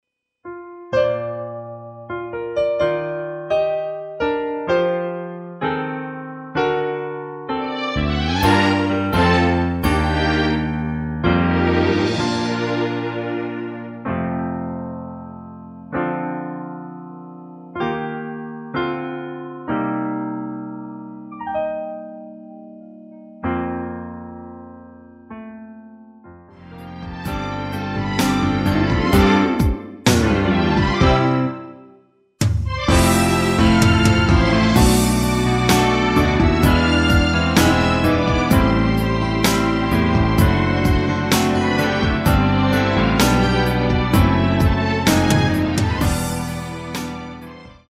MR입니다.
원곡의 보컬 목소리를 MR에 약하게 넣어서 제작한 MR이며